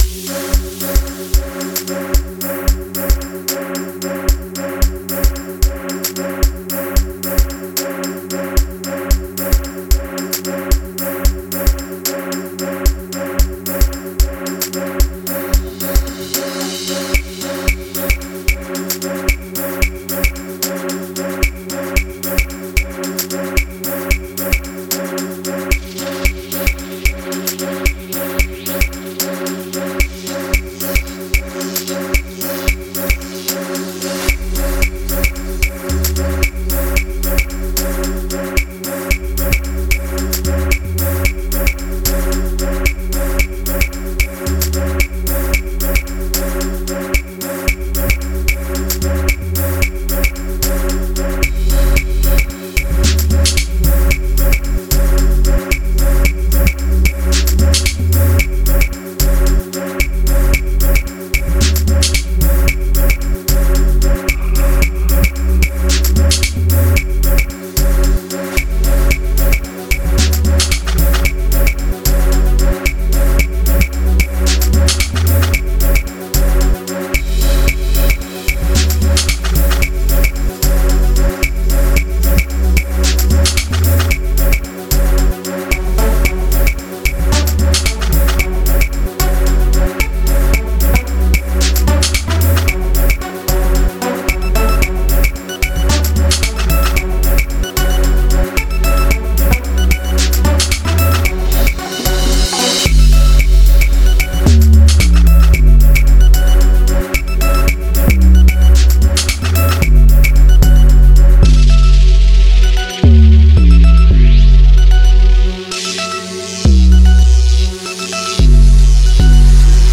06:25 Genre : Amapiano Size